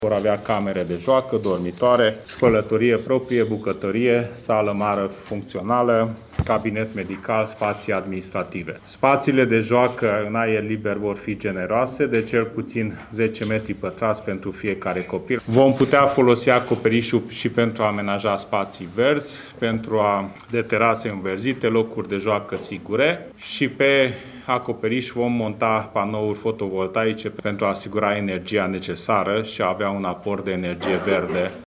Creșele vor fi eficiente energetic, spune ministrul Dezvoltării, Cseke Attila.